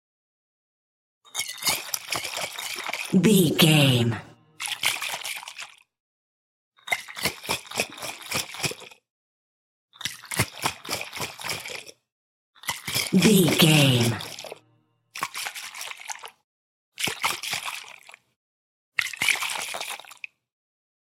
Bottle shake liquid
Sound Effects
foley